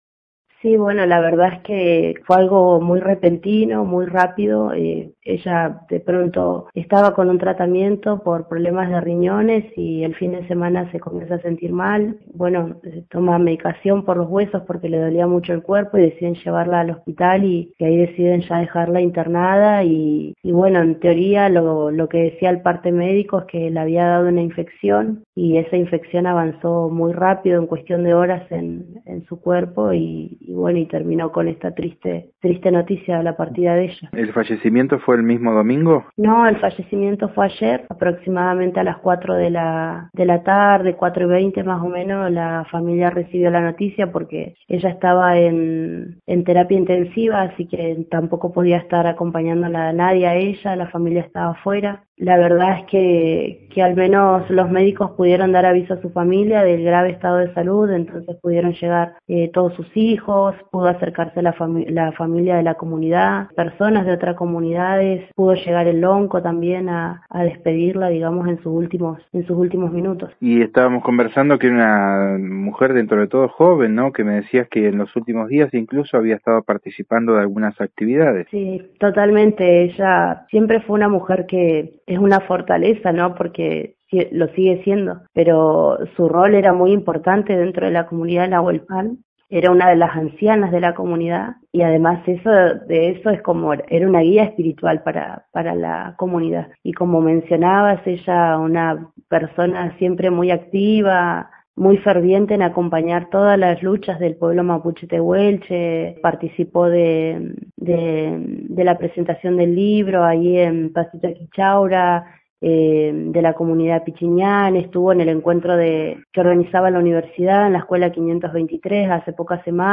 en diálogo con el móvil de Radio Nacional.